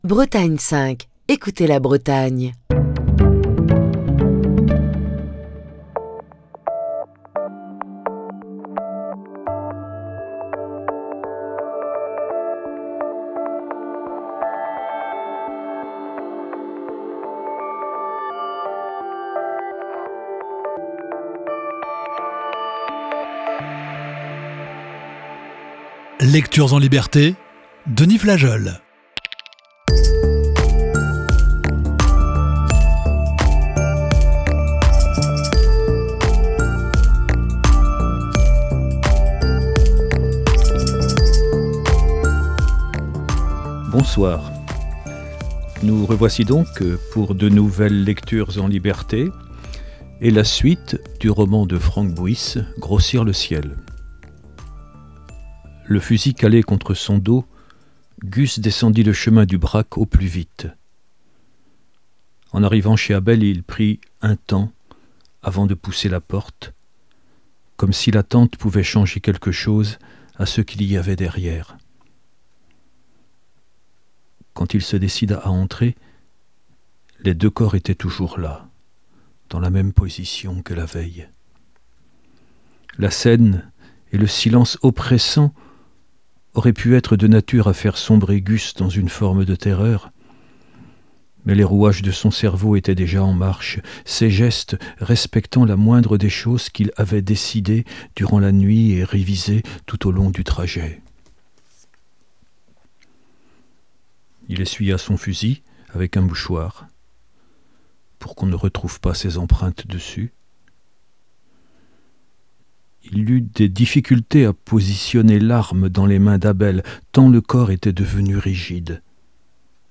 Émission du 11 octobre 2023.